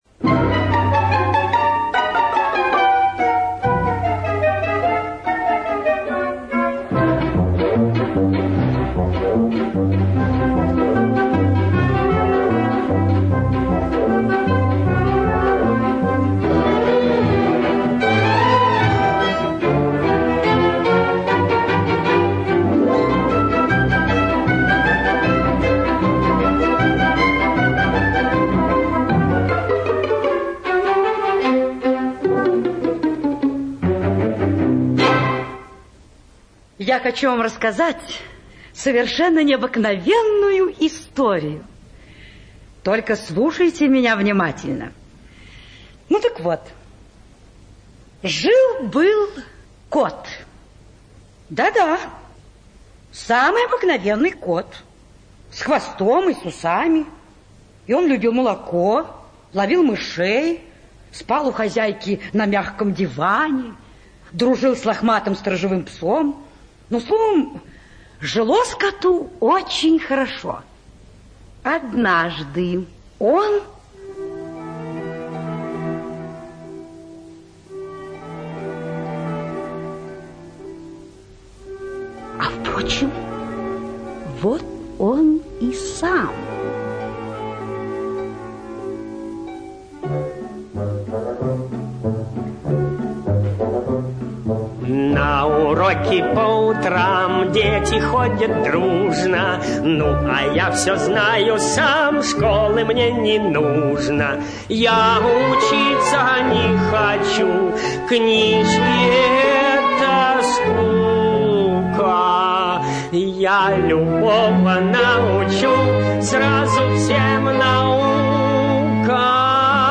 На данной странице вы можете слушать онлайн бесплатно и скачать аудиокнигу "Кот-хвастун" писателя Владимир Лёвшин.